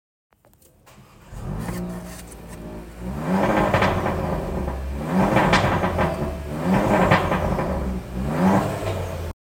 MG7 1.5T sound 🍿 sound effects free download